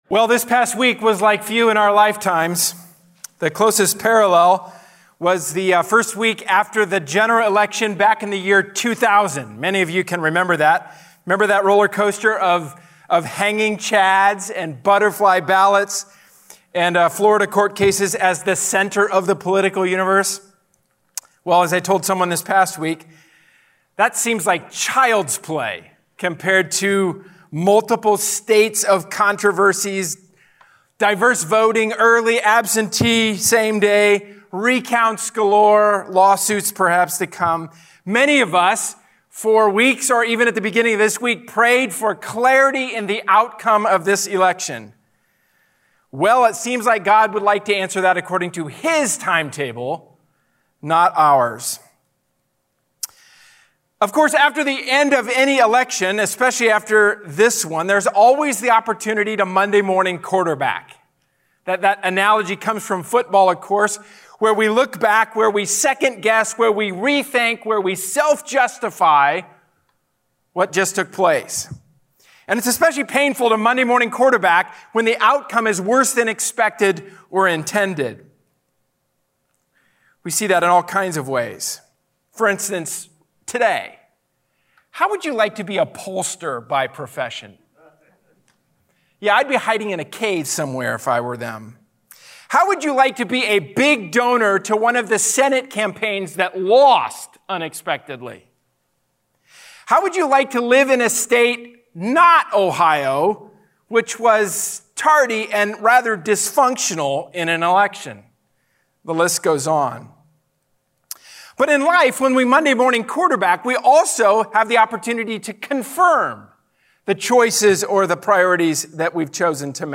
A sermon from the series "Living Out the Gospel."